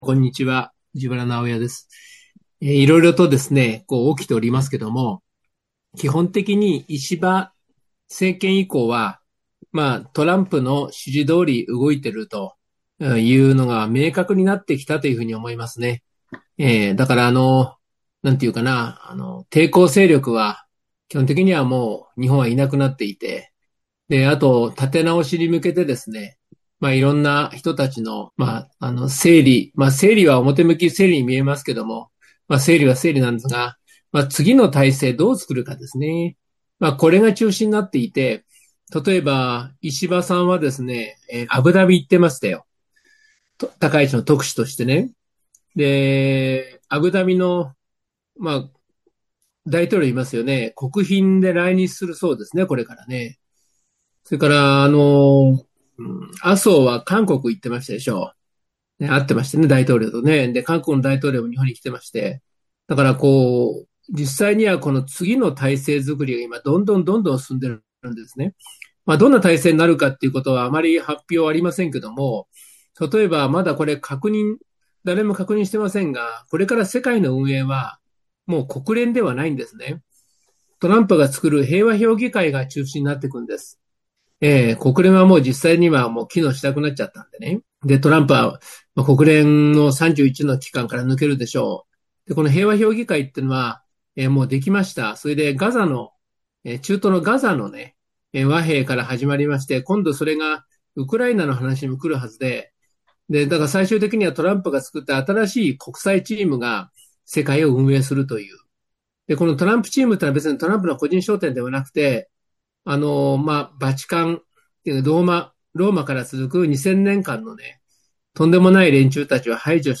第112回NSP時局ならびに日本再生戦略講演会